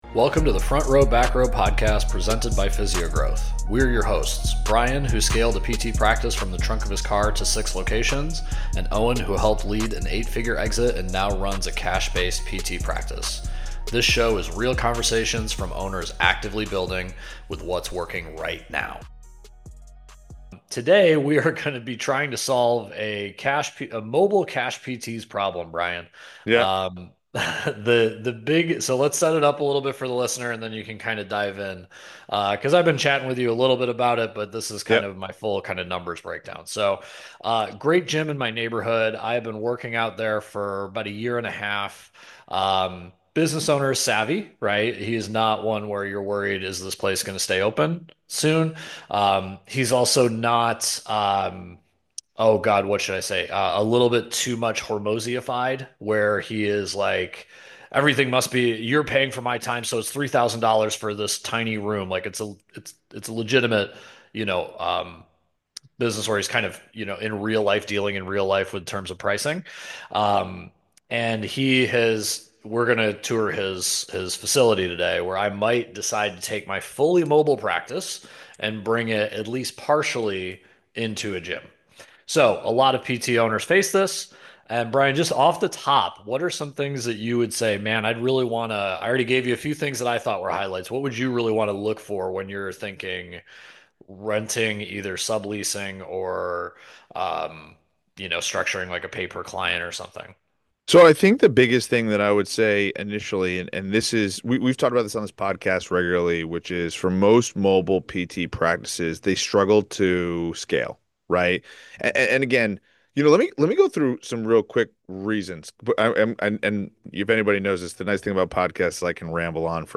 It’s a real conversation about risk, cash flow, flexibility, and protecting downside while creating upside.